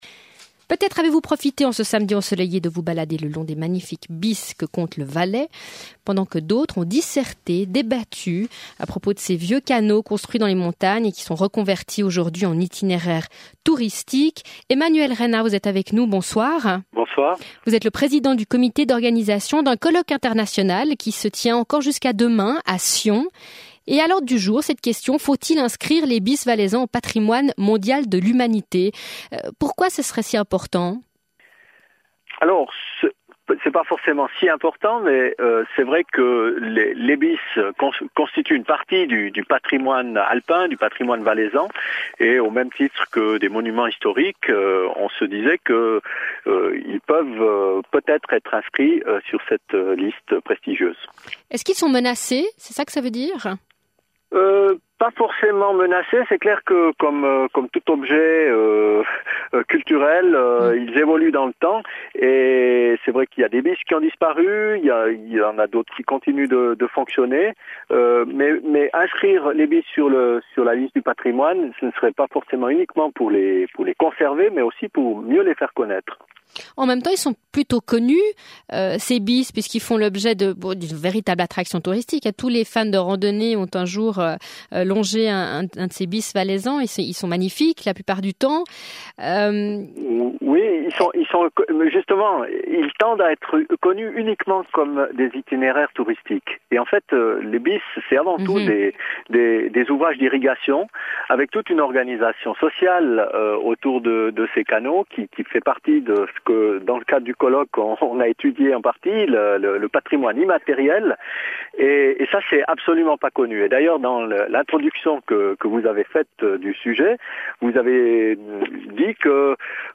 A l’ordre du jour du colloque, cette question: faut-il inscrire les bisses valaisans au patrimoine mondial de l’Humanité? L’interview